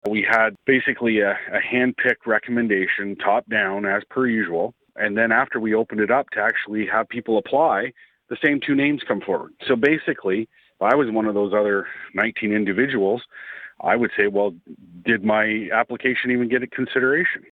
The application window was open between April 28 and May 15 and a total of 19 people applied, but the same two candidates were brought forward again, at council’s regular meeting on Tuesday afternoon.
That led Councillor Paul Carr to suggest that all of the other applicants may not have been properly considered, especially since Mayor Panciuk had noted at the original meeting that the same two names would likely be brought back, even after the public application period.